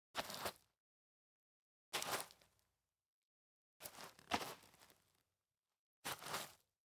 Звук горсти земли в руке nШорох грунта между пальцами nЗемля в ладони – звук прикосновения nШепот земли в сжатой руке